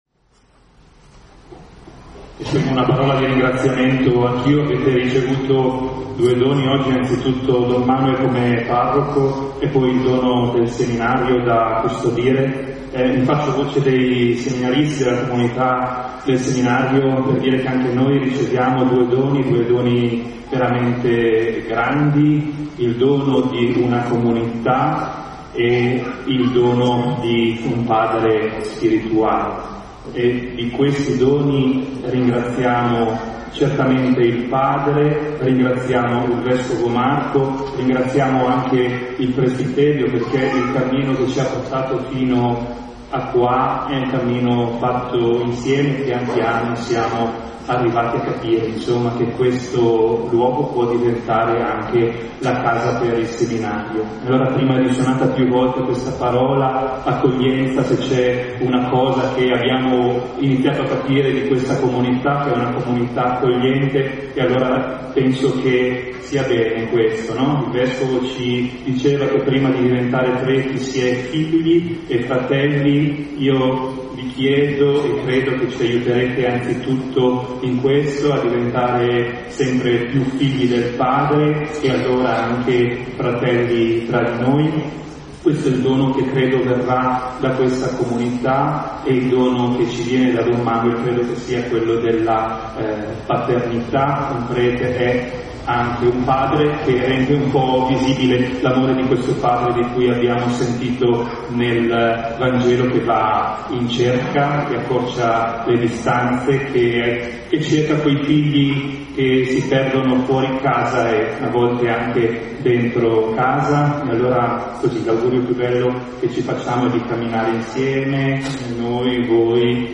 Riviviamo ora alcuni dei momenti principali della Celebrazione (work in progress):